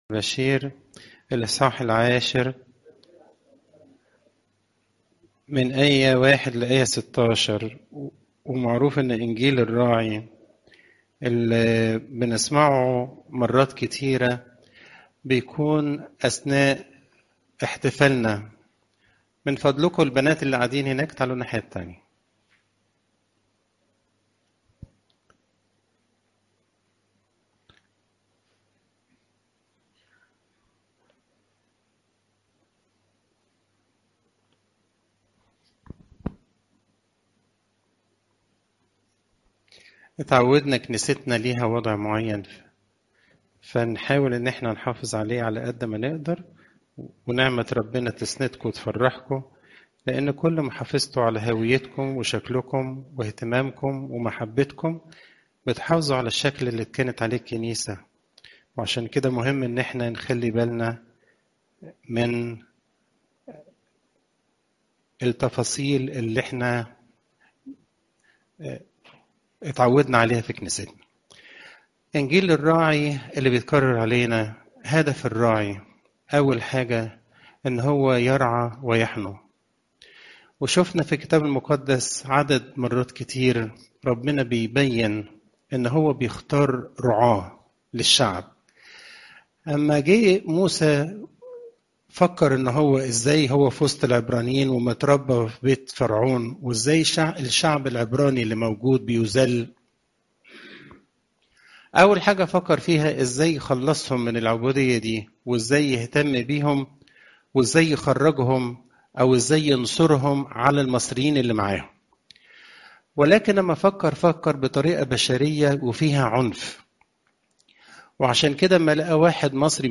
عظات قداسات الكنيسة (يو 10 : 1 - 16)